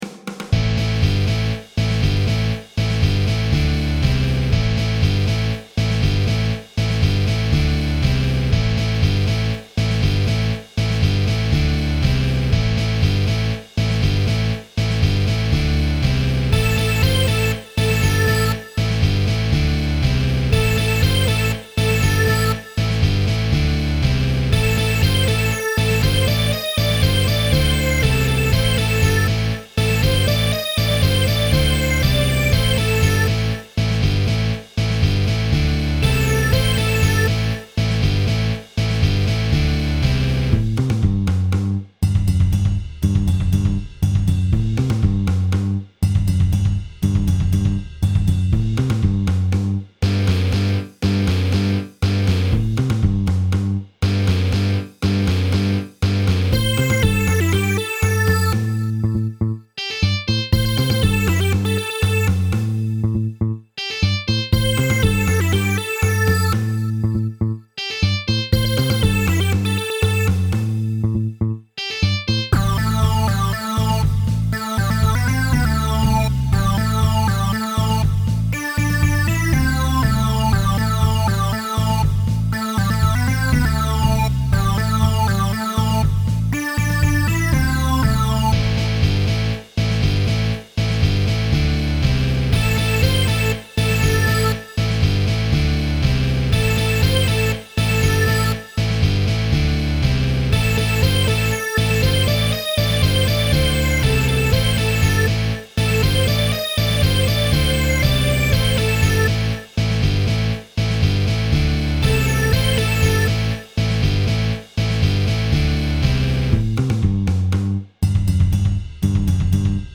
BluesRock